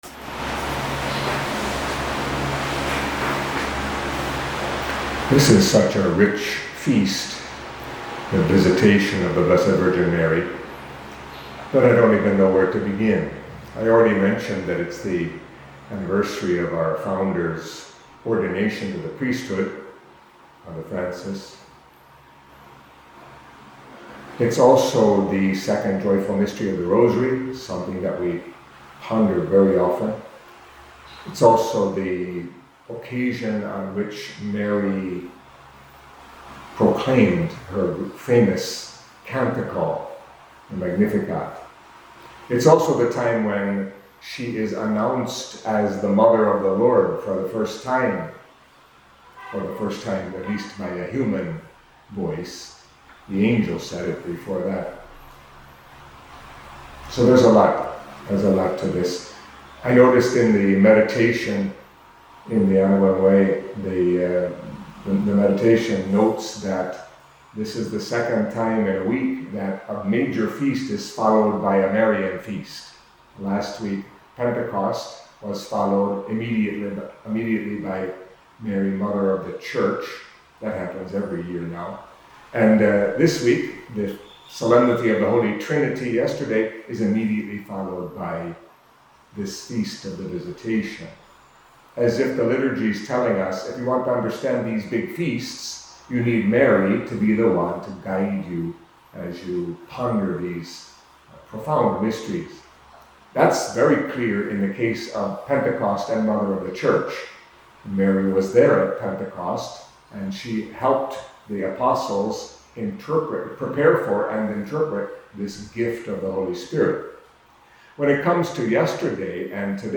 Catholic Mass homily for the Feast of The Visitation of the Blessed Virgin Mary